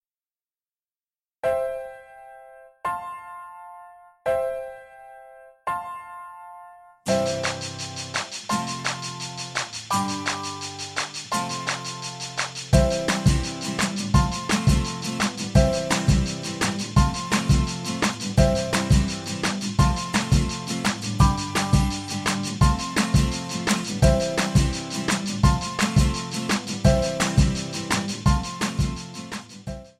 MPEG 1 Layer 3 (Stereo)
Backing track Karaoke
Pop, Rock, 2010s